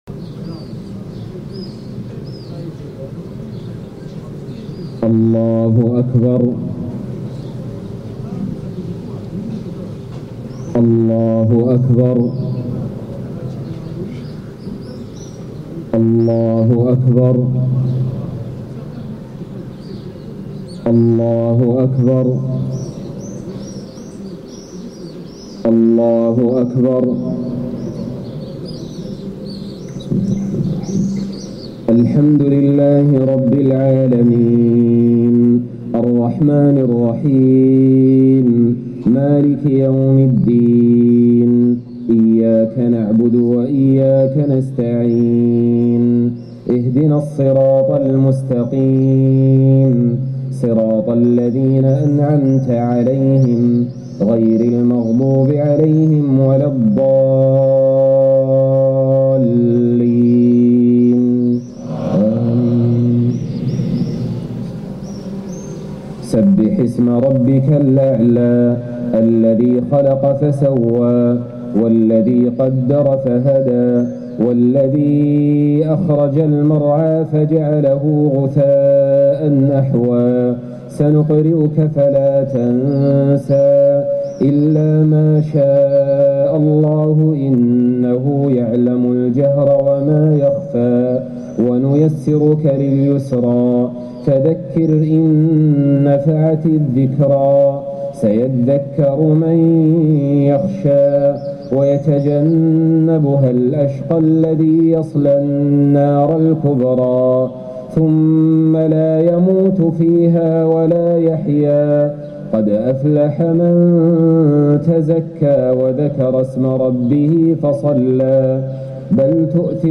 Albanian Muslims celebrating Eid al-Fitr, the end of the holy month of Ramadan.